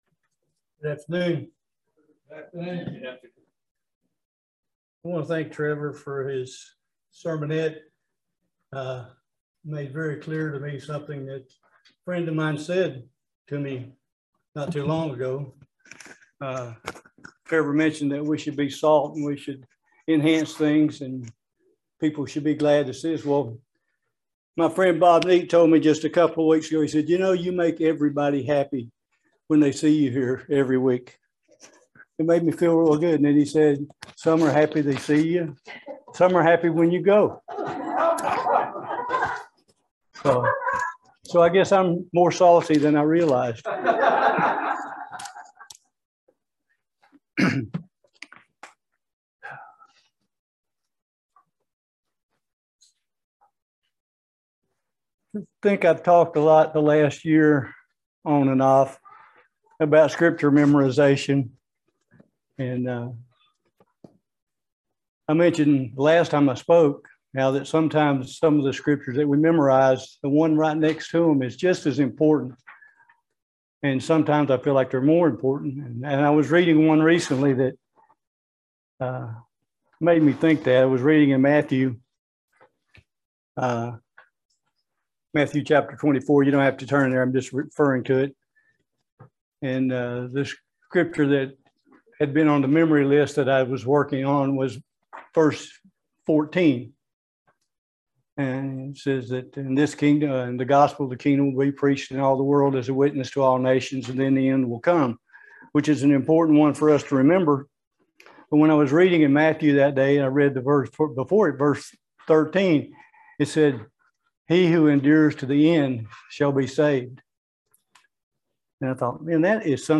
Join us for this excellent sermon enduring to the end. We must understand what it means to endure to the end , and then work to make that happen.